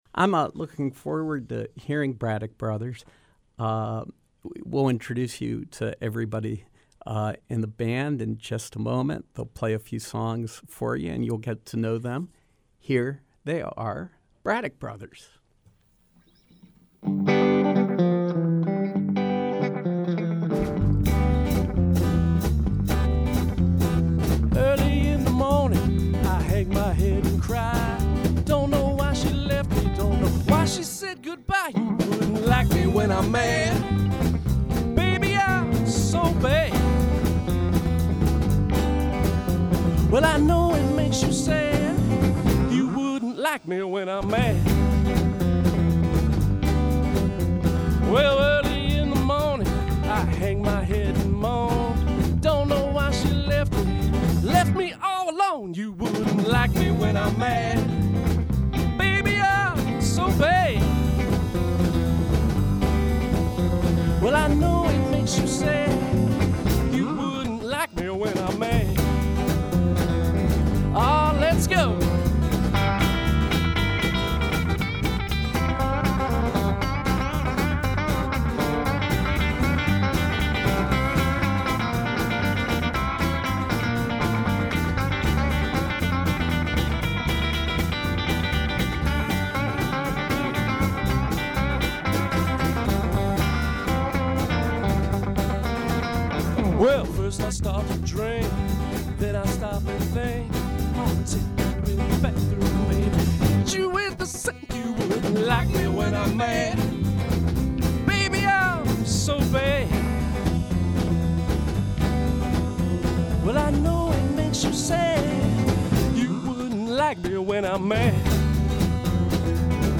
Live Rockabilly-blues music